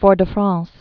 (fôr-də-fräɴs)